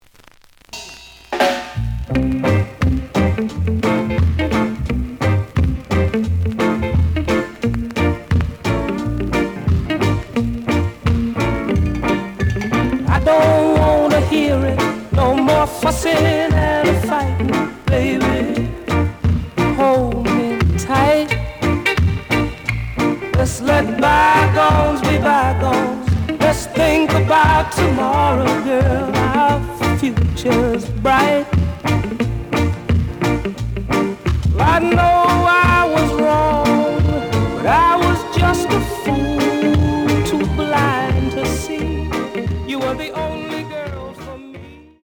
The audio sample is recorded from the actual item.
●Genre: Rock Steady
Some damage on both side labels. Plays good.)